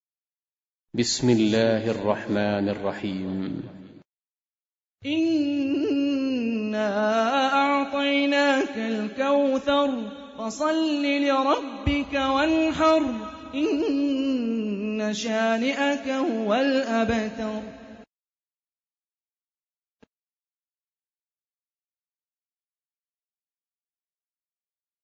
Surah Repeating تكرار السورة Download Surah حمّل السورة Reciting Murattalah Audio for 108.